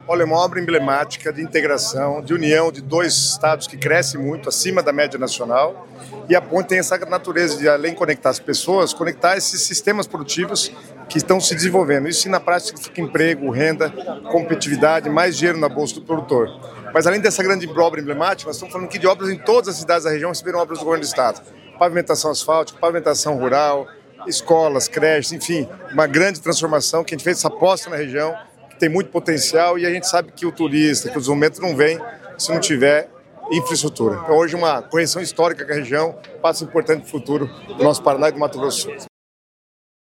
Sonora do secretário das Cidades, Guto Silva, sobre o anteprojeto da ponte que vai conectar Paraná e Mato Grosso do Sul | Governo do Estado do Paraná